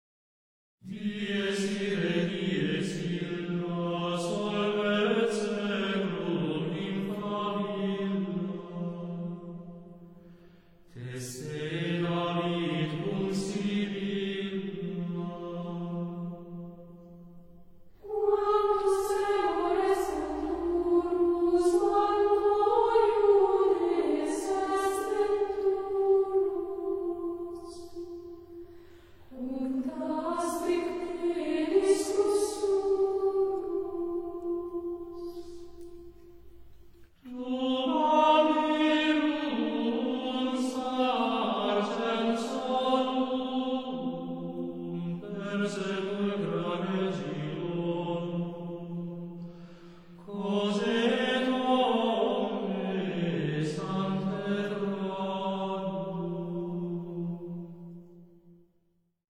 Aurora Surgit – Ego sum Resurrectio: Gregorian Chant for the Dead
Хоралы заупокойной мессы и дня всех святых. 1996 г.